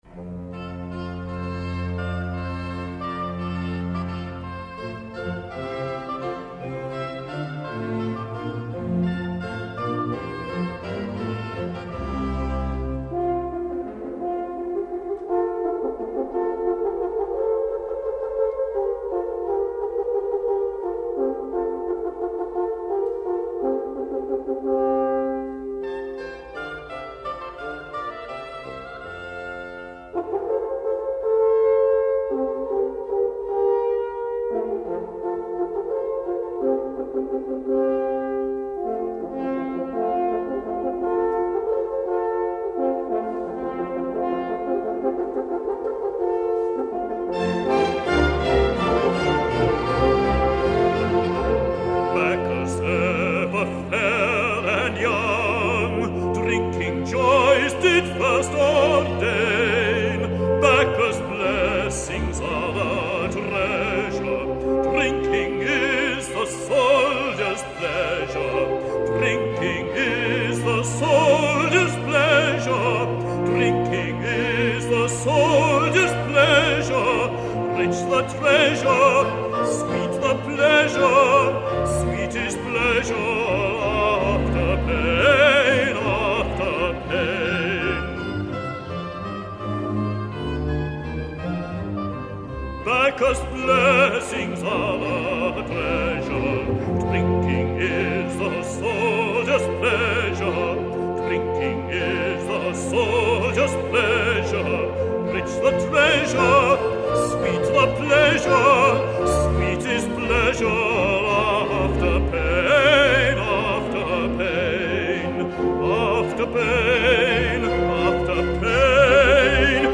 Bass Air & Chorus